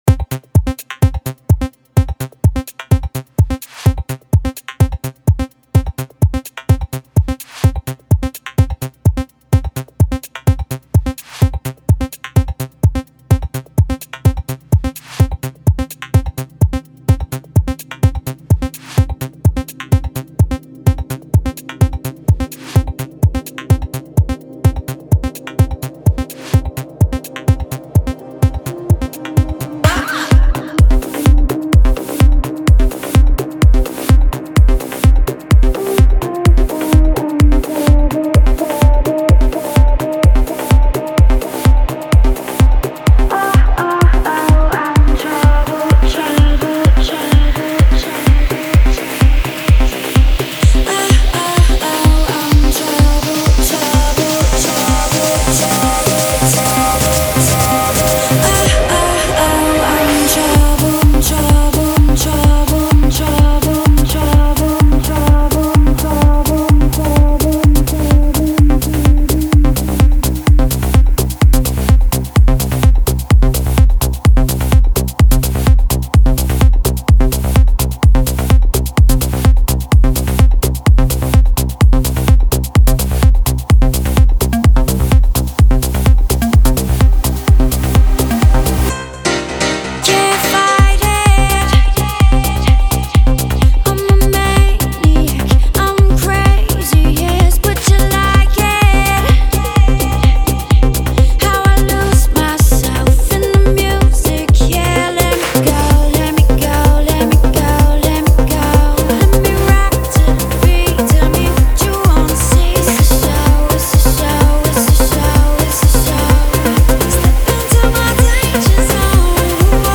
Жанр: Trance | Progressive